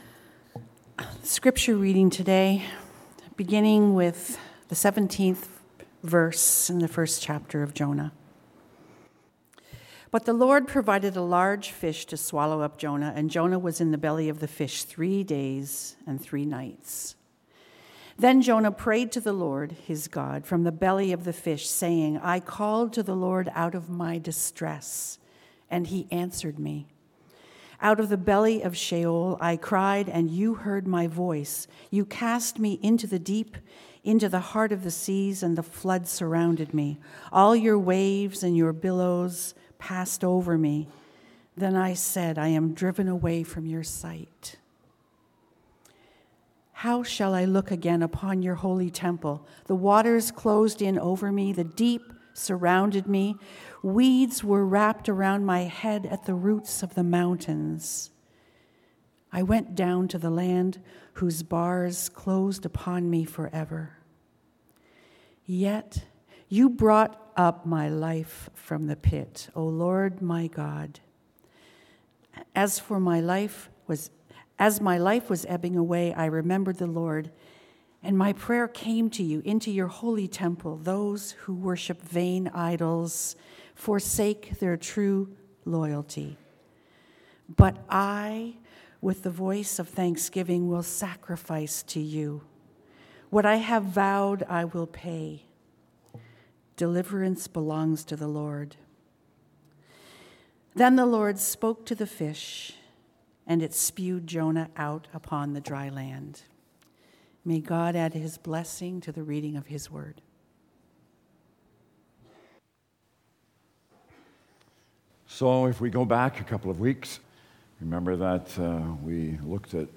sermon_aug12.mp3